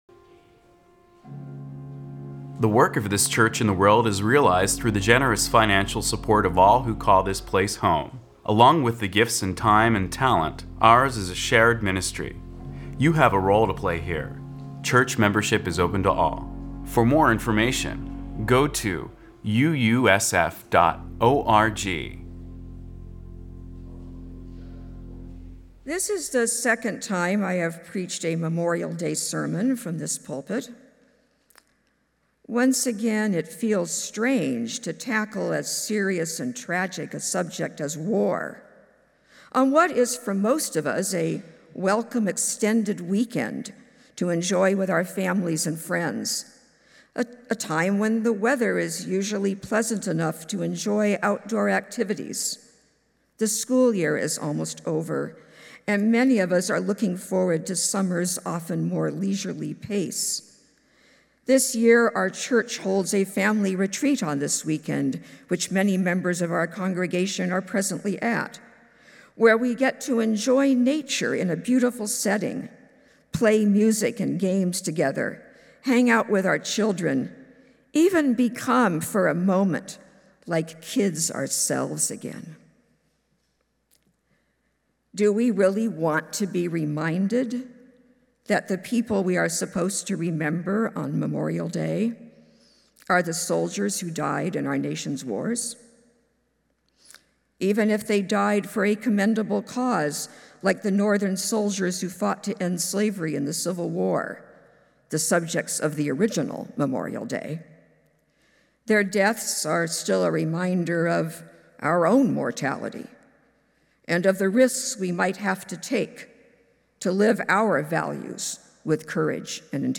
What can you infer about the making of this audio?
First Unitarian Universalist Society of San Francisco Sunday worship service.